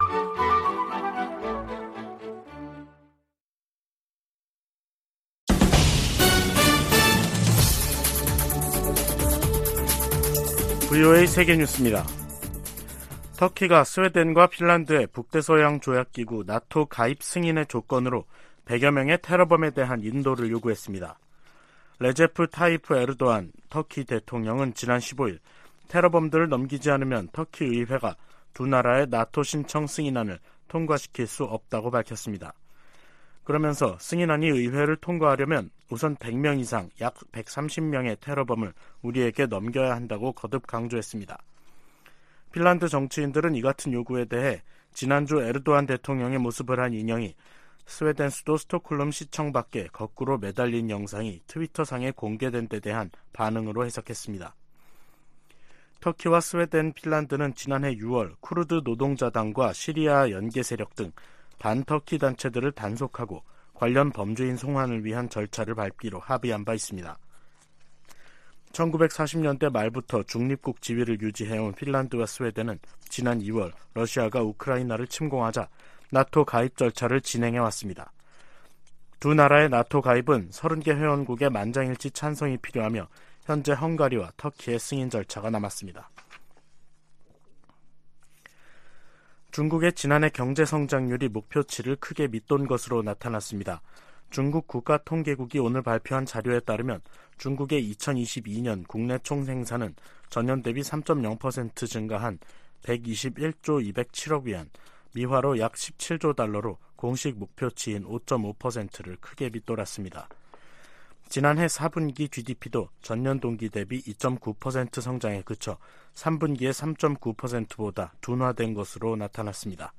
VOA 한국어 간판 뉴스 프로그램 '뉴스 투데이', 2023년 1월 17일 2부 방송입니다. 미국 해군참모총장은 한국의 ‘자체 핵무장’ 안과 관련해 미국의 확장억제 강화를 현실적 대안으로 제시했습니다. 유엔은 핵보유국 의지를 재확인한 북한에 긴장 완화를 촉구하고 유엔 결의 이행과 외교를 북핵 문제의 해법으로 거듭 제시했습니다.